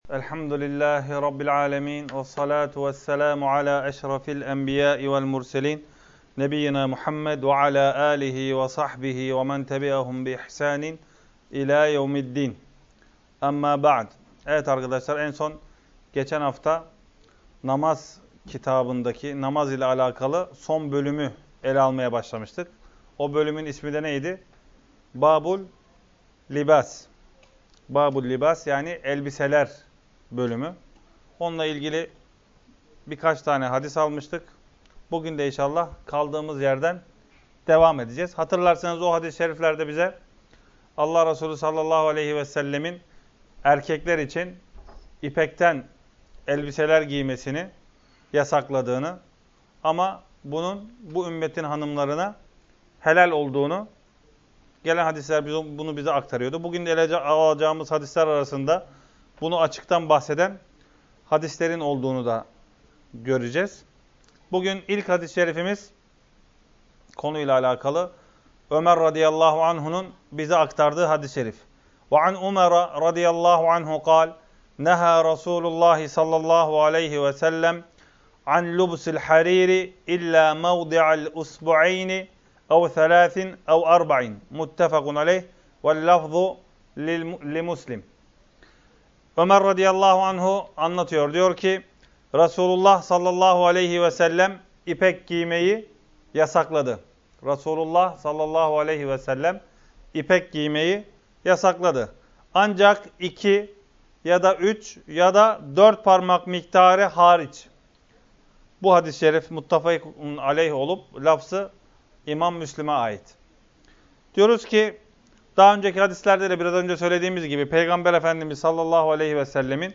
2. Ders - 17- Yâni Giymesi Helâl Ve Haram Olan Elbise Bölümü